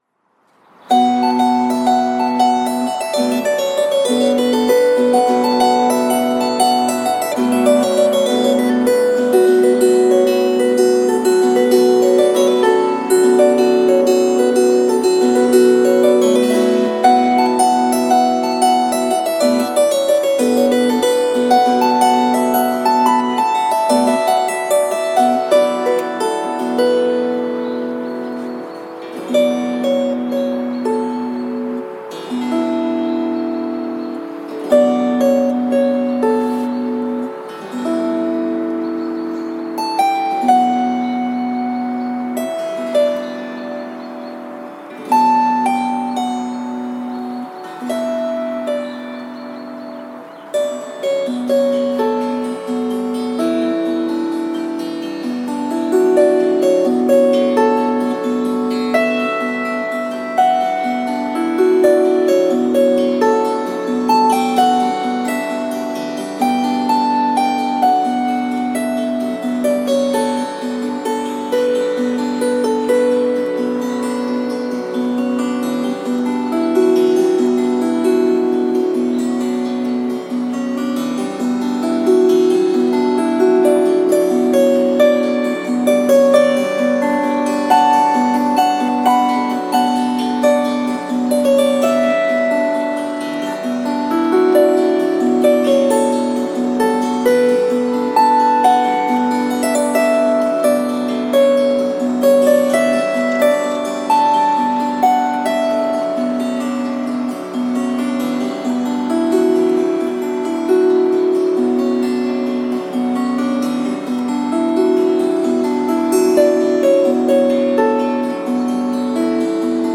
Послушайте эту мелодию на гуслях